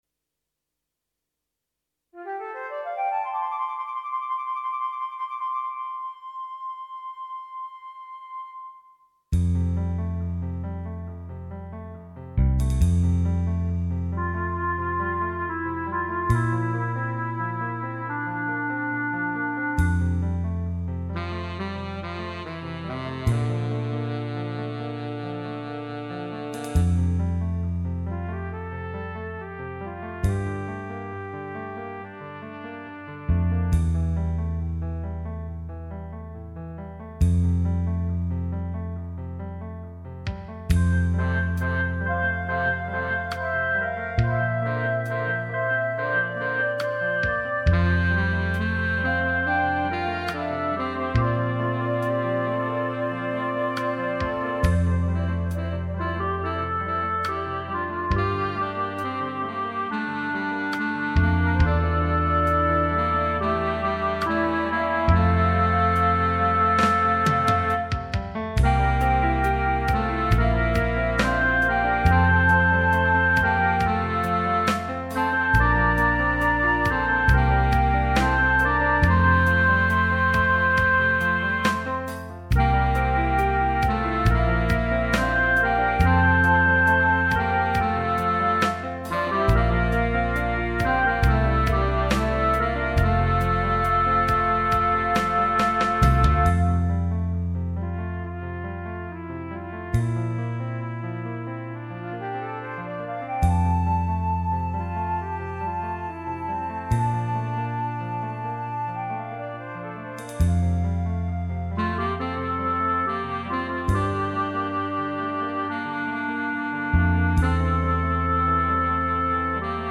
minus Instrument 5